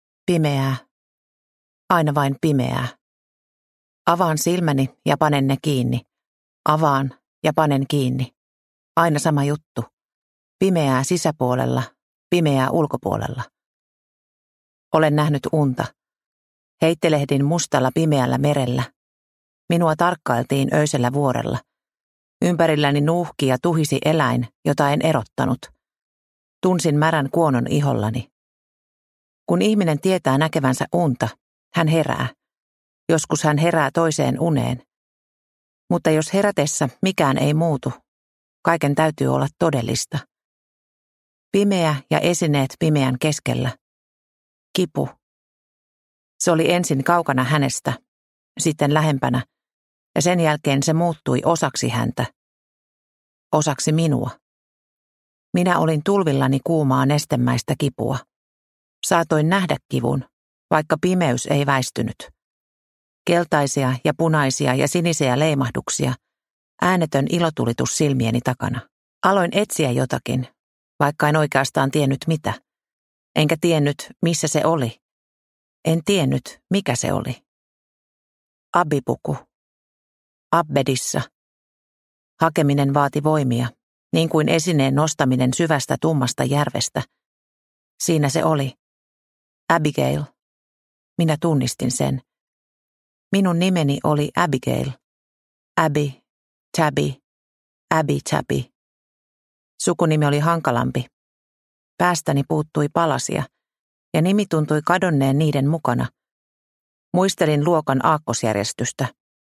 Kylmän kosketus – Ljudbok – Laddas ner